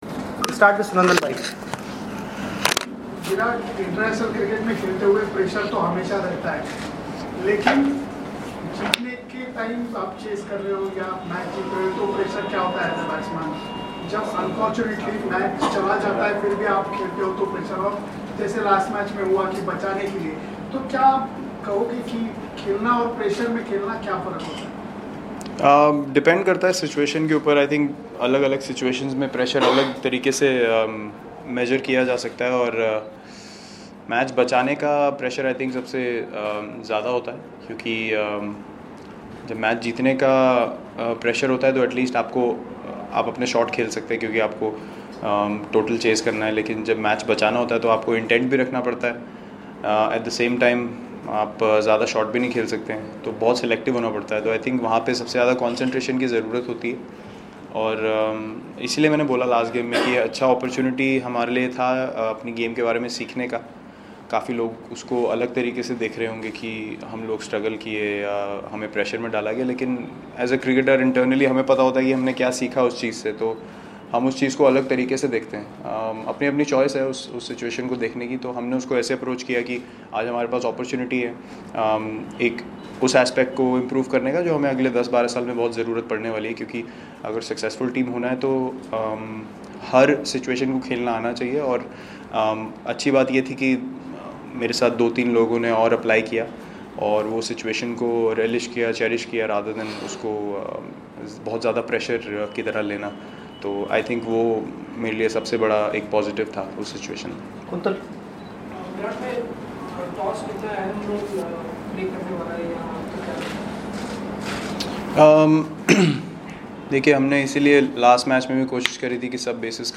Virat Kohli's pre-match press confrence